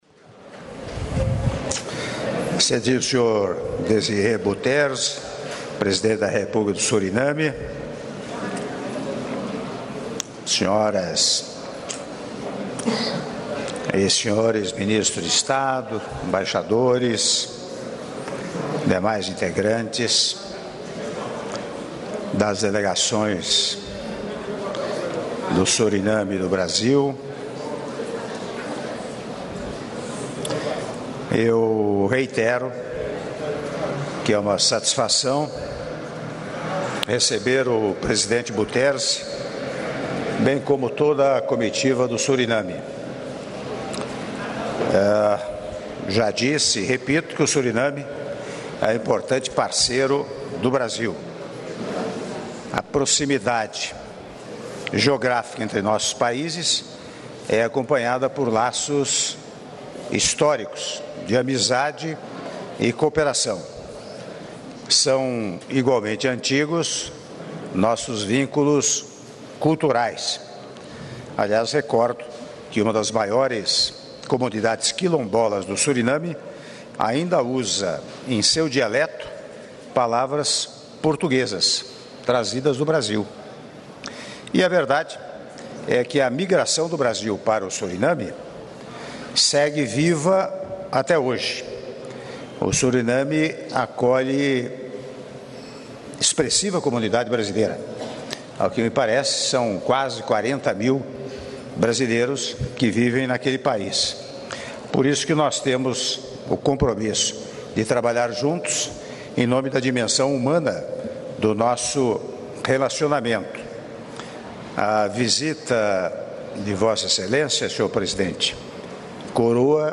Áudio do brinde do Presidente da República, Michel Temer, durante o almoço oferecido em homenagem ao Senhor Desiré Delano Bouterse, Presidente da República do Suriname -Brasília/DF- (03min05s)